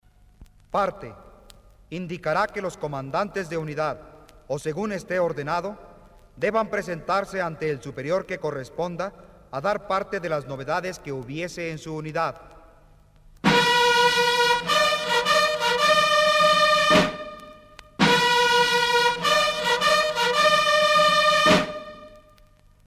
TOQUES MILITARES REGLAMENTARIOS EN MP3.